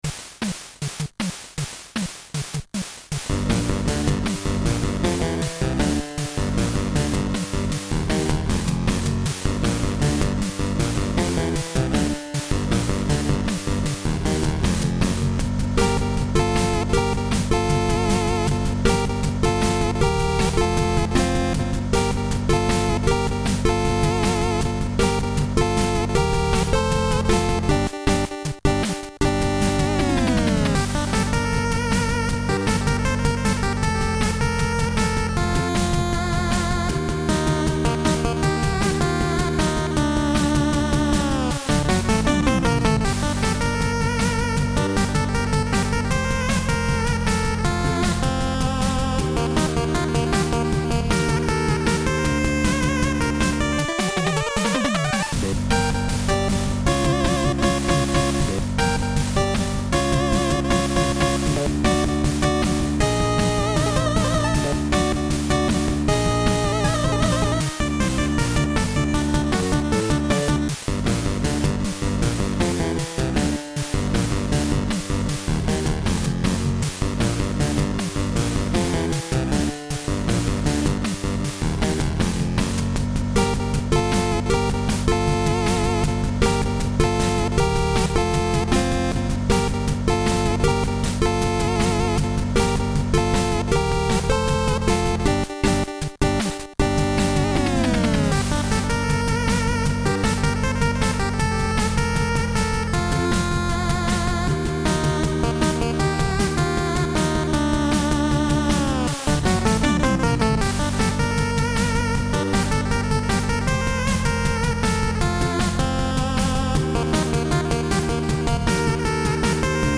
Der Ton von der anderen Kamera hat recht iwi so gewummert.